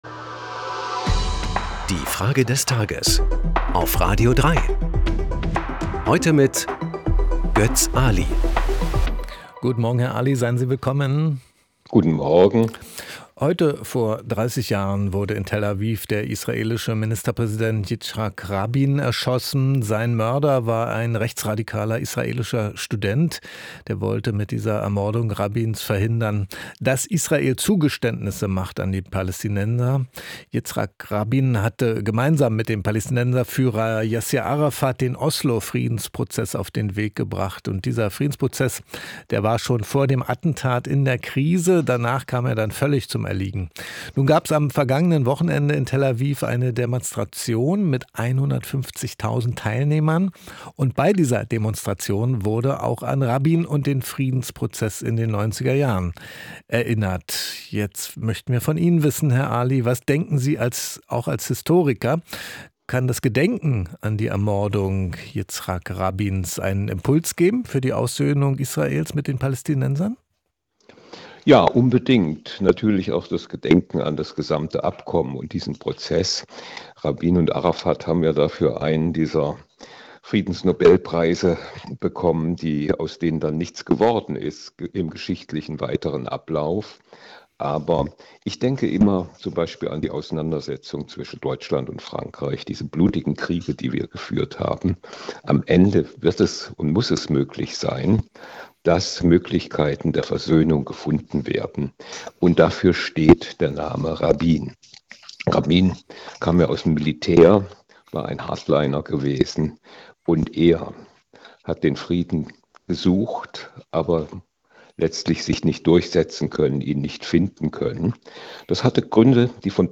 Es antwortet der Historiker und
Politikwissenschaftler Götz Aly.